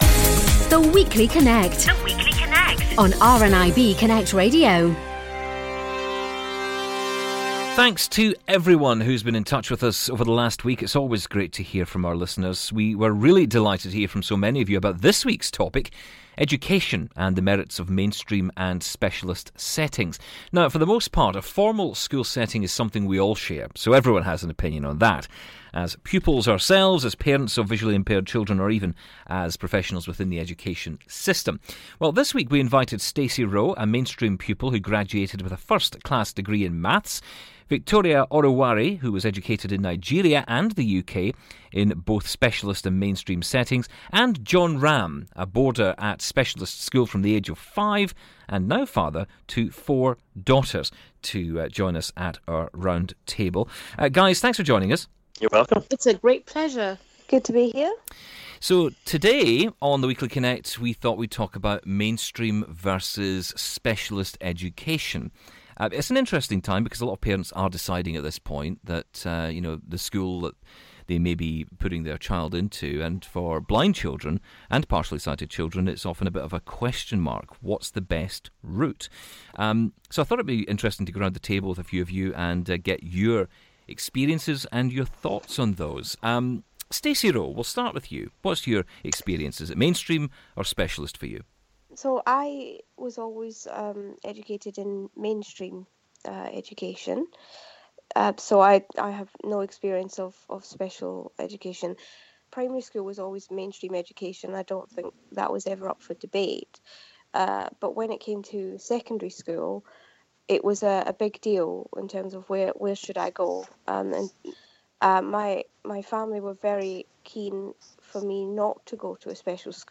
Discussion: Mainstream vs Specialist Schools - Part 1
We turn the spotlight on mainstream and specialist schools. Our round table guests have a wealth of experiences and not just in the UK!